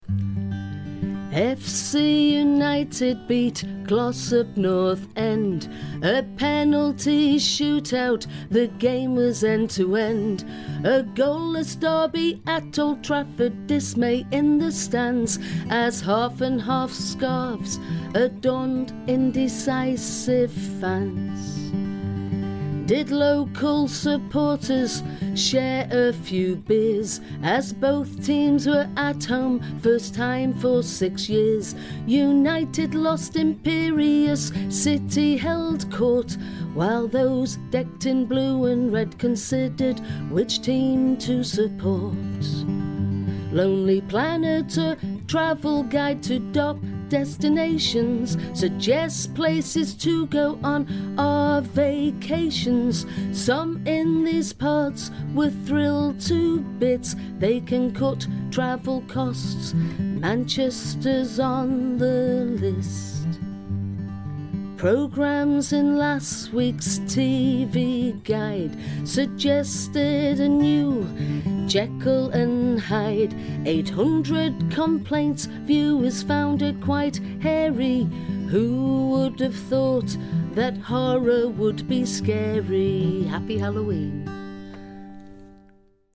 This week's news in song